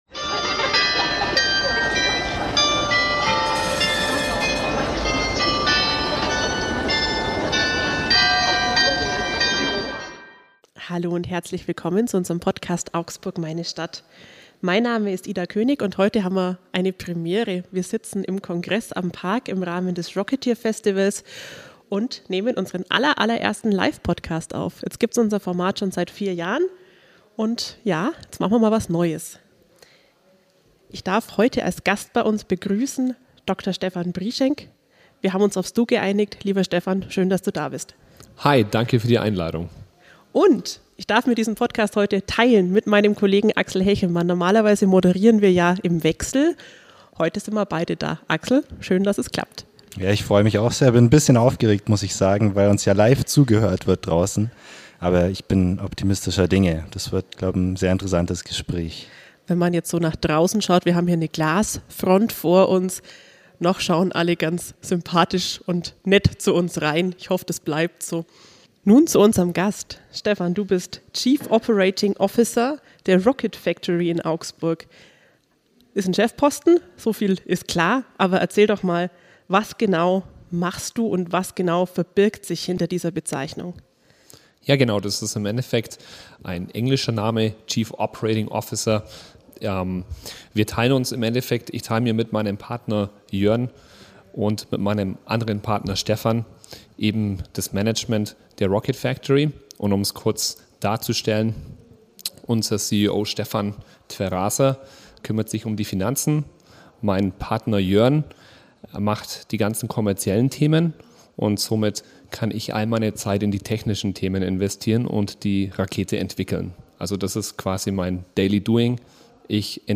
Zum ersten Mal in über vier Jahren "Augsburg, meine Stadt" zeichneten sie einen Podcast live vor Publikum auf. Und zwar beim Rocketeer-Festival im Kongress am Park.